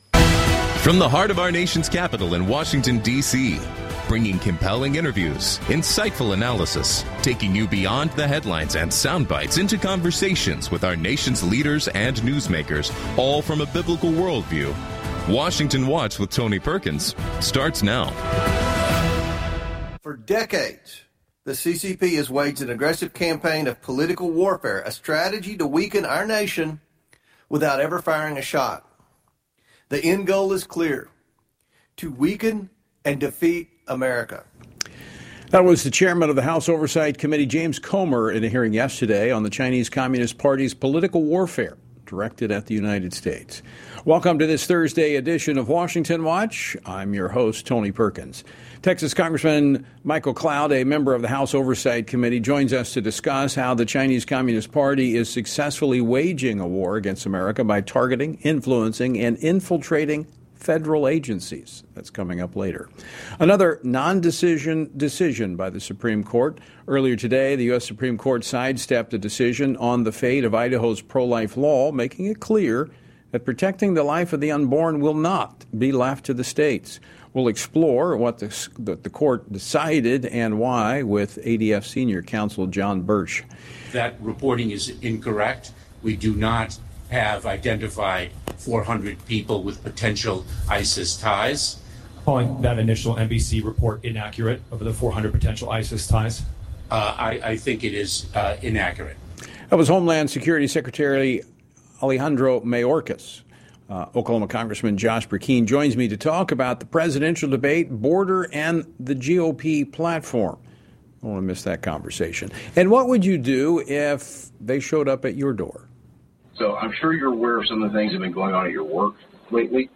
Washington Watch is a daily program hosted by Family Research Council President Tony Perkins that covers the issues of faith, family and freedom.